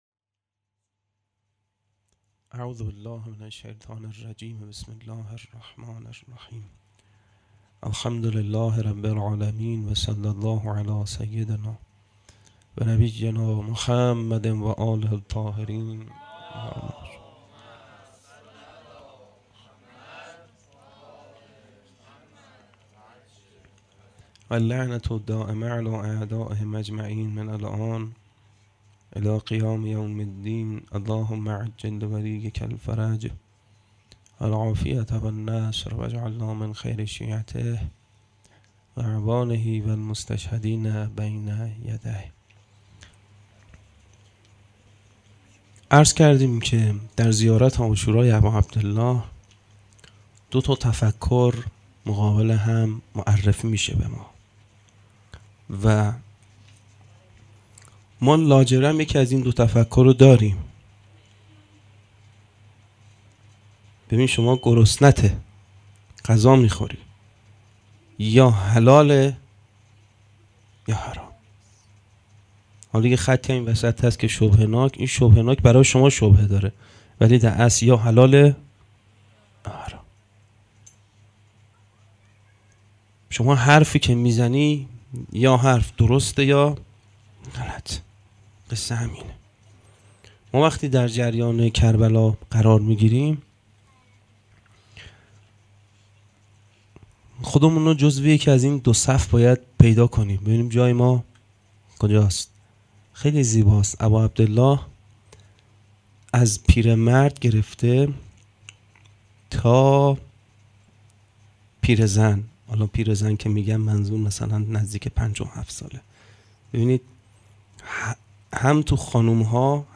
هيأت یاس علقمه سلام الله علیها
شب هفتم محرم الحرام 1441